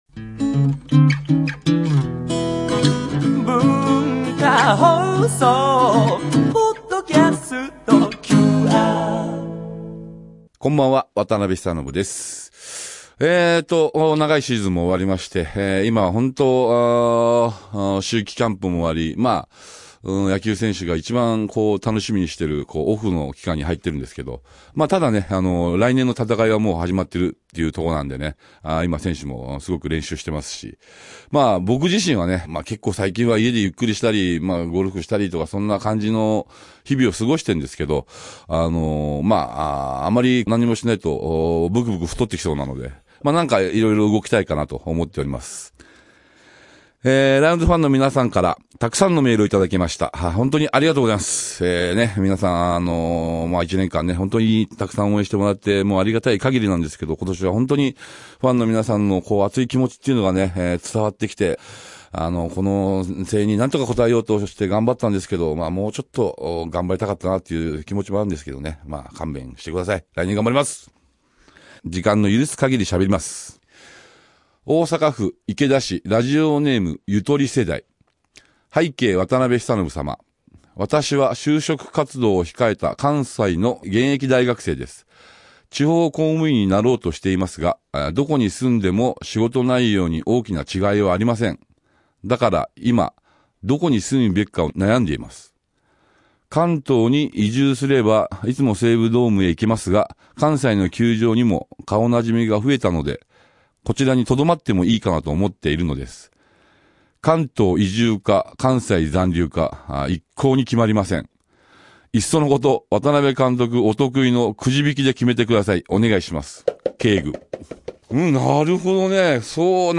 古き良き時代のDJっぷりは 深夜のAMラジオを聴いているかのような 錯覚にとらわれます。 若き日のやんちゃぶりから監督業の苦悩まで、 実に幅広い話題を楽しめるはずです。